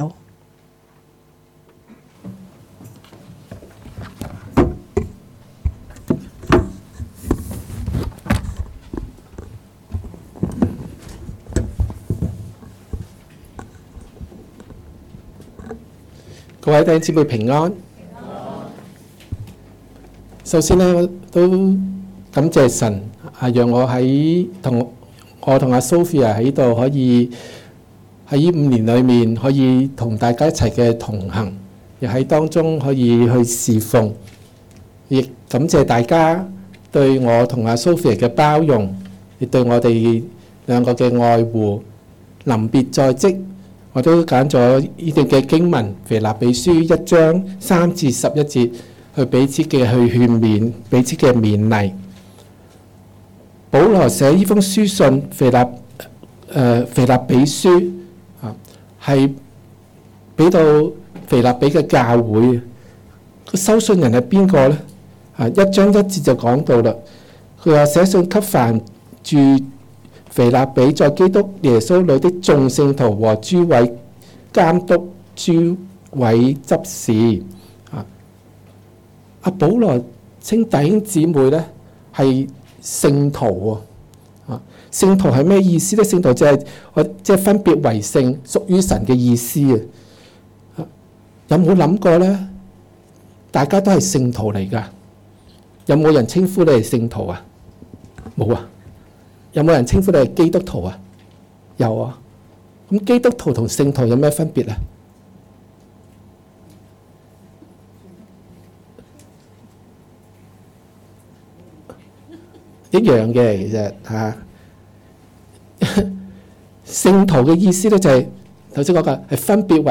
2016年8月13日及14日崇拜
崇拜講道(舊)